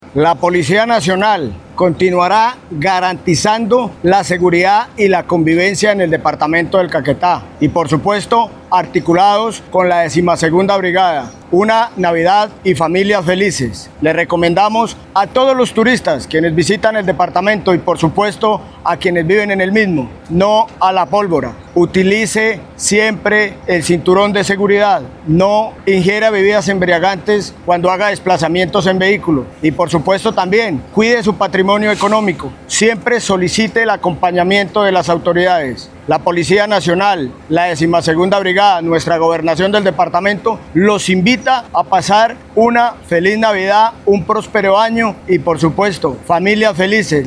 AUDIO_CORONELES_EJERCITO_POLICIA_-_copia.mp3